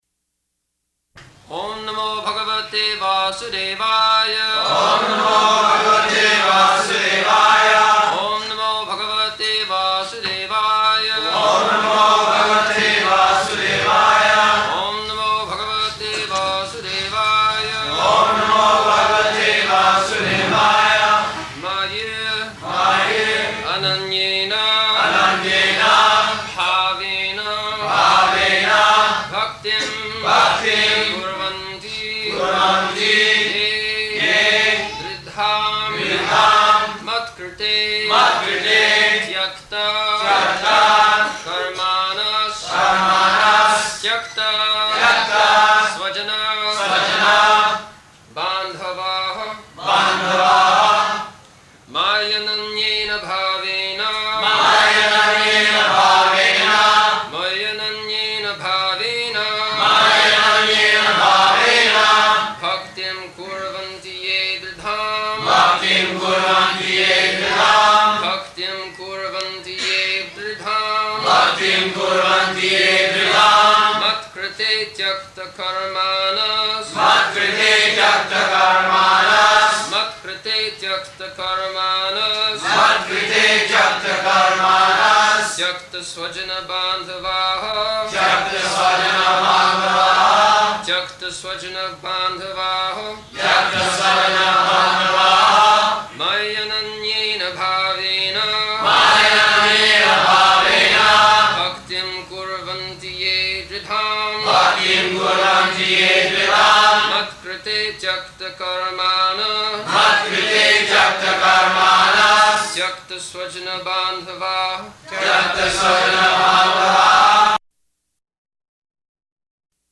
-- Type: Srimad-Bhagavatam Dated: November 22nd 1974 Location: Bombay Audio file
[devotees repeat] [leads chanting of verse, etc.]